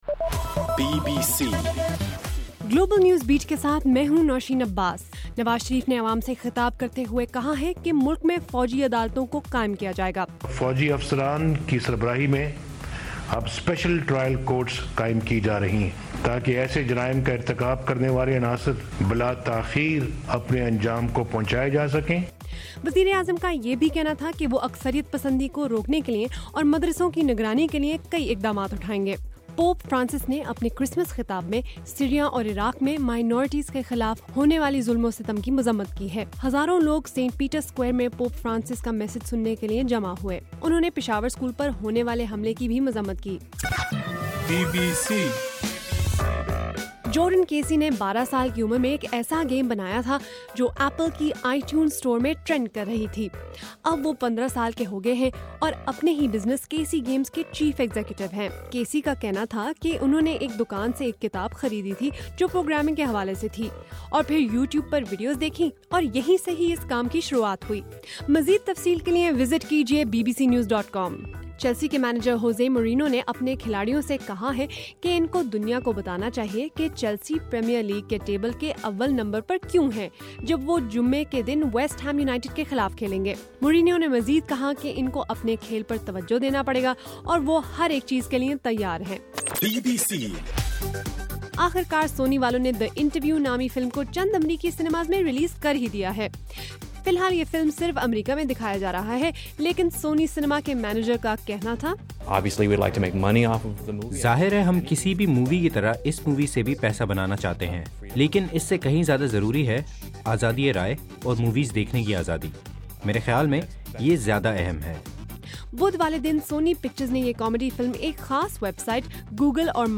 دسمبر 25: رات 9 بجے کا گلوبل نیوز بیٹ بُلیٹن